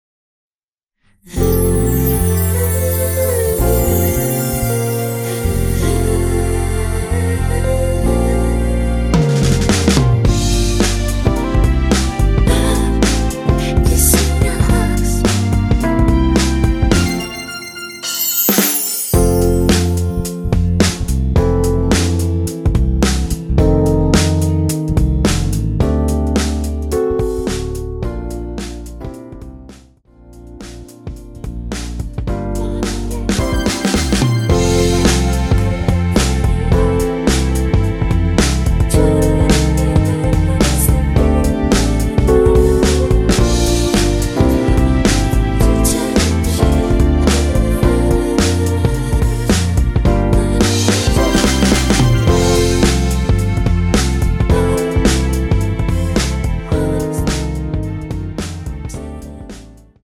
원키 코러스 포함된 MR입니다.
앞부분30초, 뒷부분30초씩 편집해서 올려 드리고 있습니다.